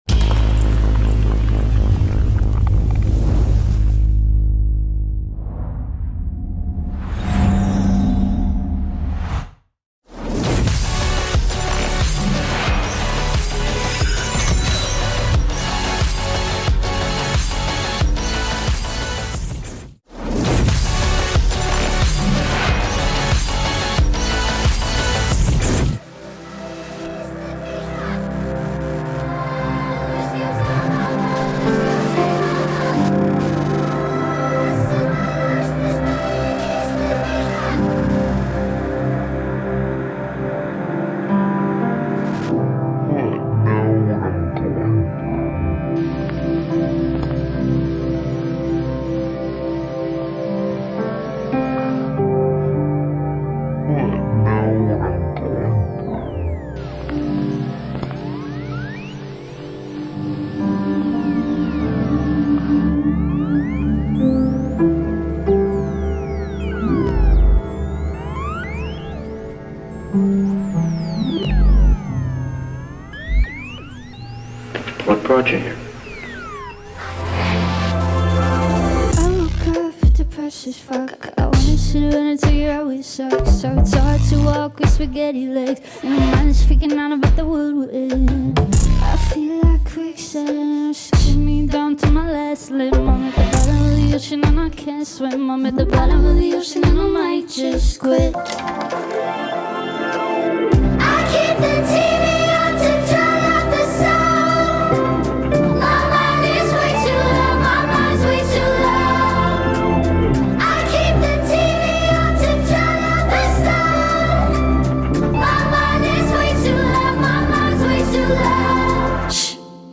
Genre: Dubstep , House , Trap